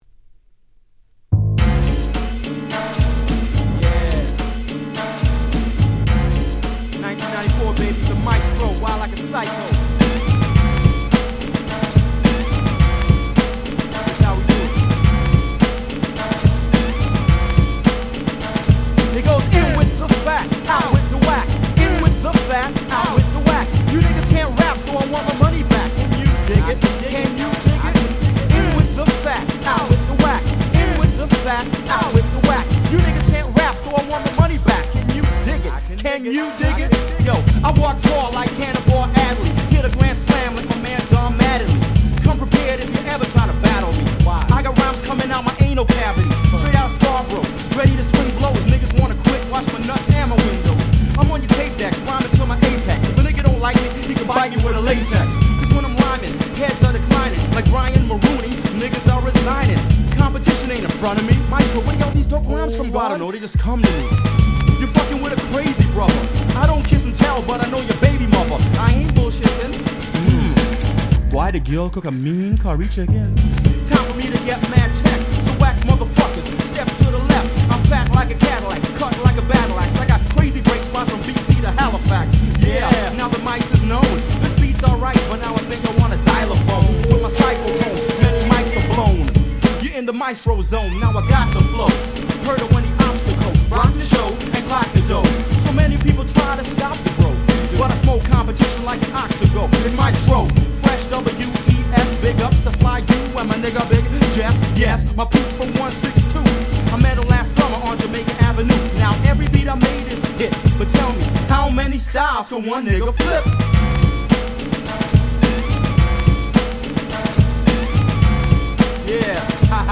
Canadian Hip Hop最高峰！！
コチラも上記の2曲と甲乙付け難いDopeなBoom Bapチューンで言う事無し！！